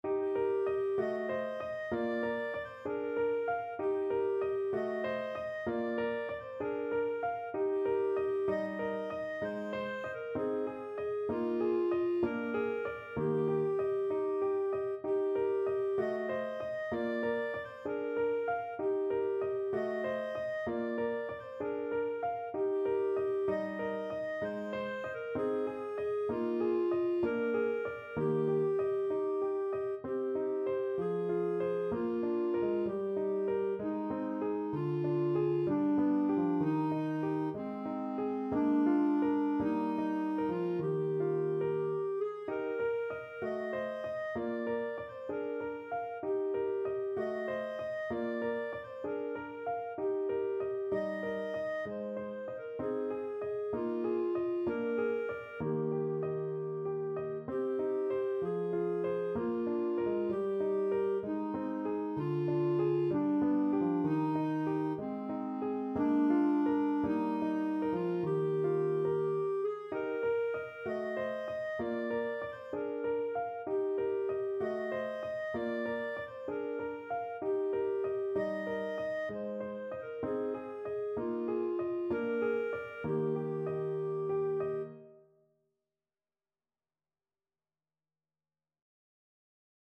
Clarinet
2/4 (View more 2/4 Music)
Eb major (Sounding Pitch) F major (Clarinet in Bb) (View more Eb major Music for Clarinet )
Cantabile = c. 64